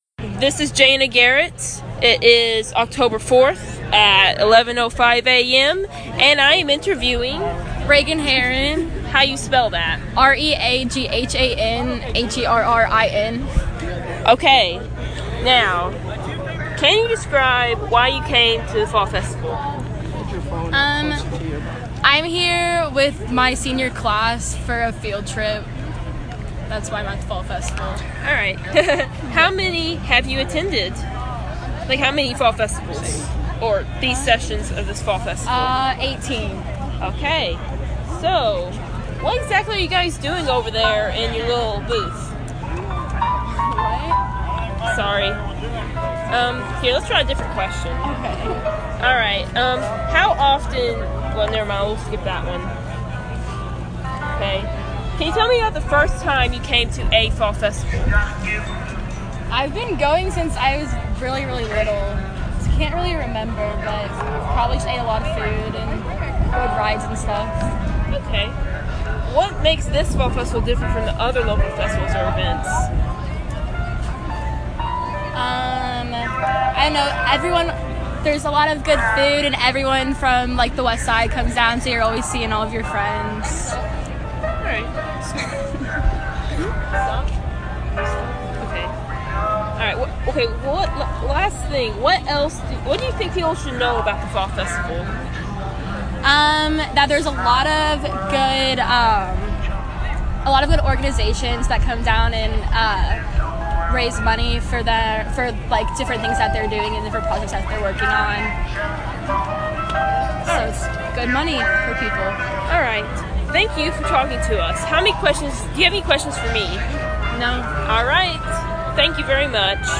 University Archives & Special Collections > Oral History Collection